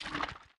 Minecraft Version Minecraft Version 1.21.5 Latest Release | Latest Snapshot 1.21.5 / assets / minecraft / sounds / block / sculk / spread2.ogg Compare With Compare With Latest Release | Latest Snapshot